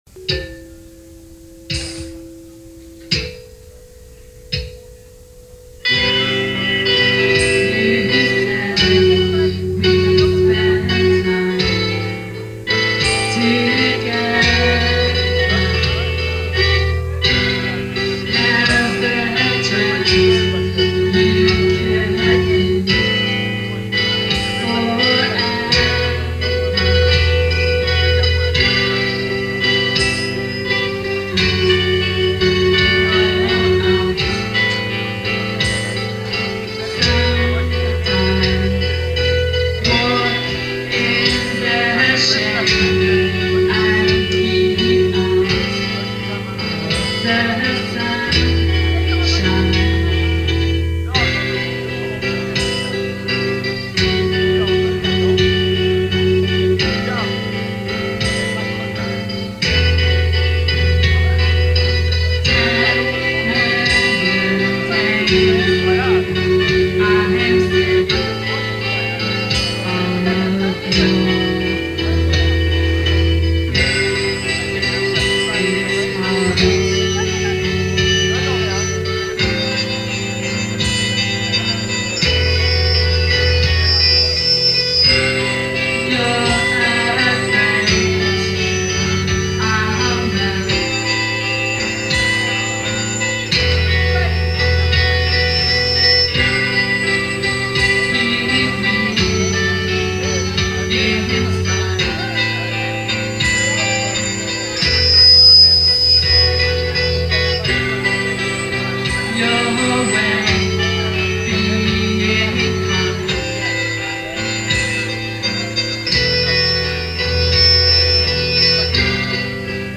was recorded in Belgium in 1991